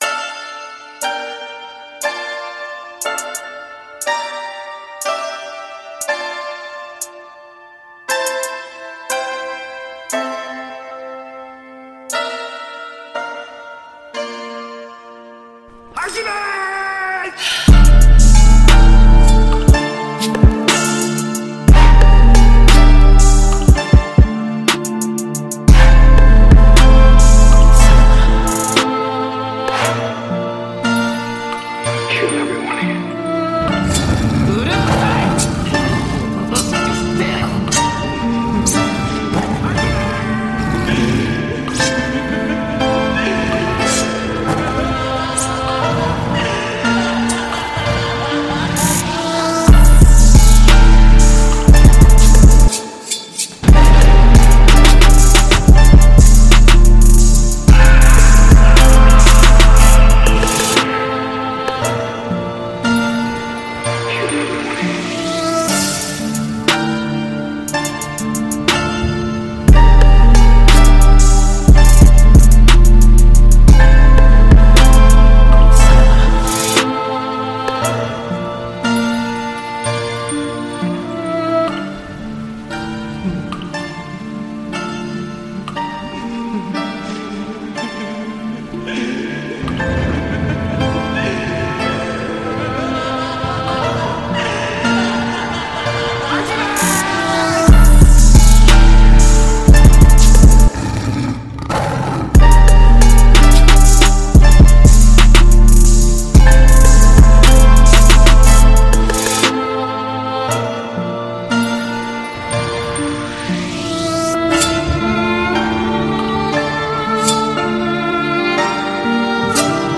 Жанр: Jazz & Blues